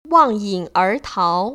[wàng yĭng ér táo] 왕잉얼타오  ▶